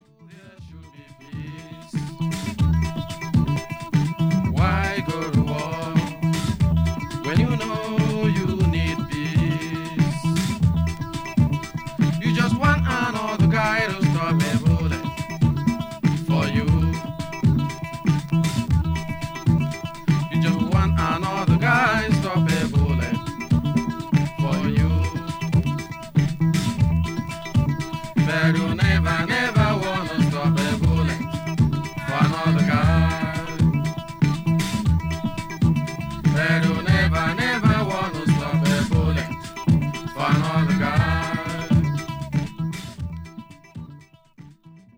afro experiments and anthems
International